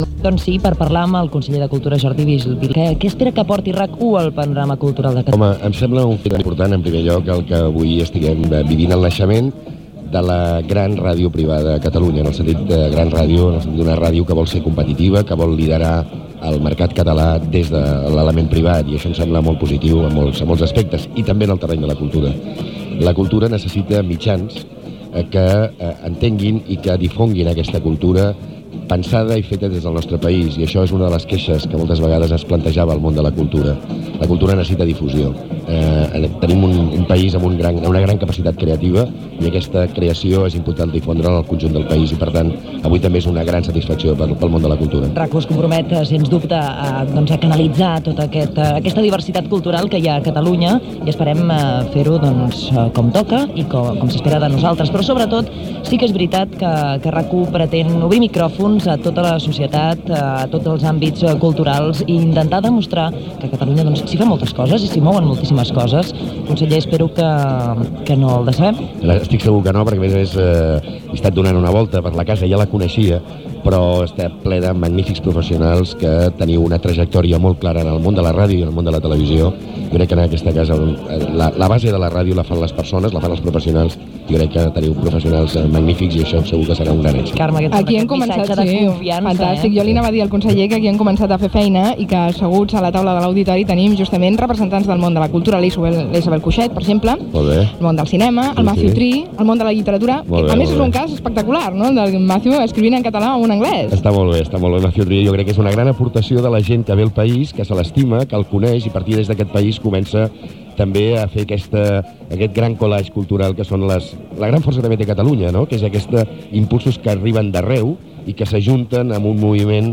b18f75520a09d5be305b36316cbcfbb99c9884fd.mp3 Títol RAC 1 Emissora RAC 1 Barcelona Cadena RAC Titularitat Privada nacional Descripció Declaracions del Conseller de Cultura Jordi Vilajoana el dia de la inauguració de RAC1.